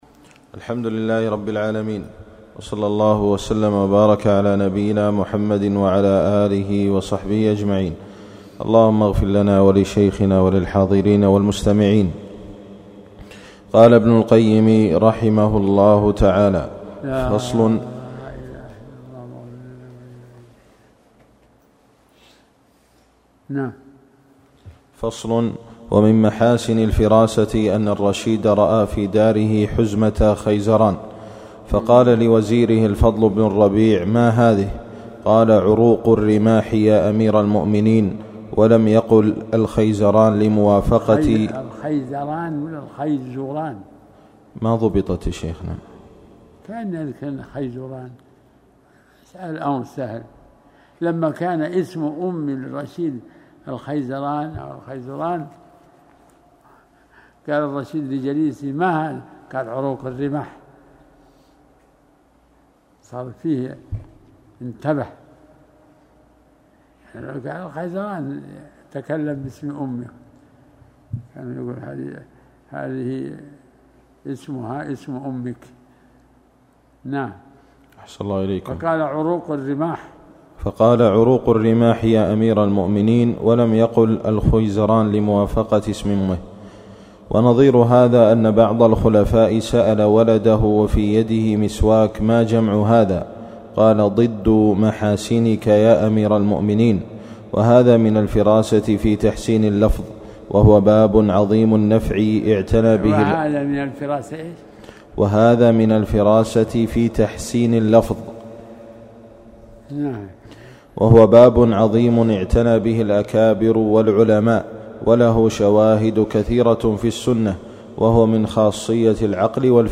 الدرس الثالث عشر